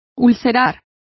Complete with pronunciation of the translation of ulcerates.